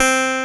Index of /90_sSampleCDs/USB Soundscan vol.09 - Keyboards Old School [AKAI] 1CD/Partition B/13-HONN.CLA3